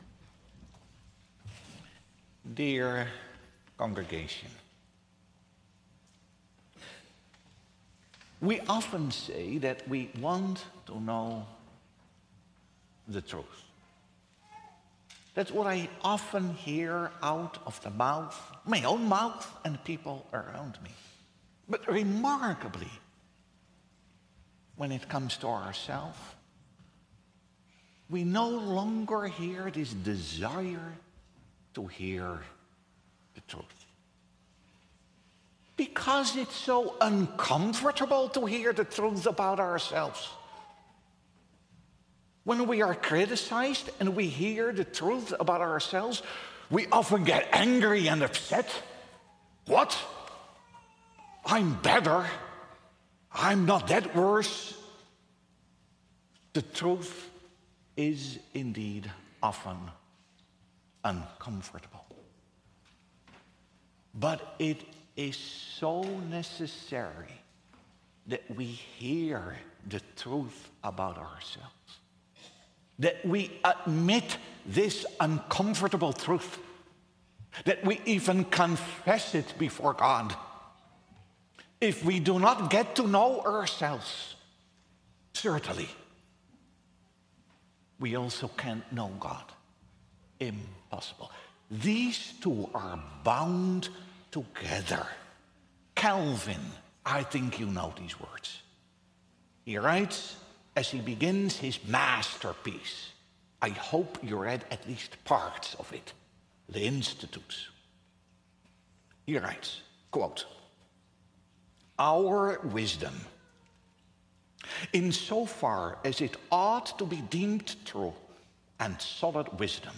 Sermon Audio | Providence Reformed Church